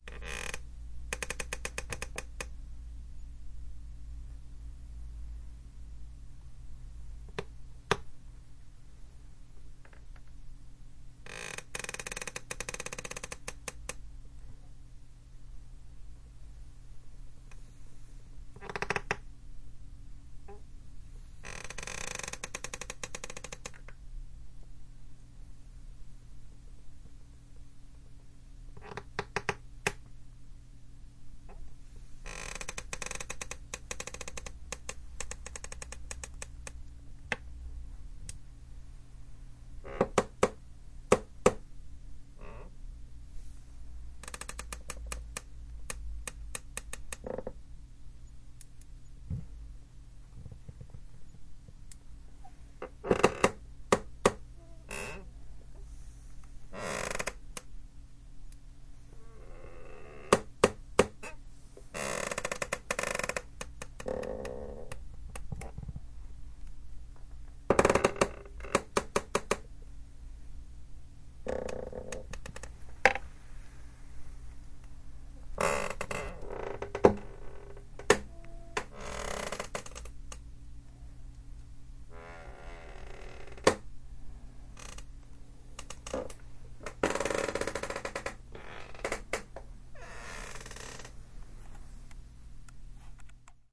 声音设计SFX项目 " 塑料对塑料的吱吱声（旧办公椅）。
描述：椅子吱吱声：旧办公椅的吱吱声和吱吱声。不同强度和持续时间的持续吱吱声。使用带有立体声胶囊的Zoom H6录音机录制。声音经过后处理以消除一些噪音。录制在3米x 4米x 2.5米的房间内。当坐在椅子上时，记录器被握在手中，声音起源于座位和腿部相遇的地方。
标签： 家具 椅子 OWI 吱吱声 吱吱 办公椅 塑料 流行 叽叽嘎嘎 吱吱
声道立体声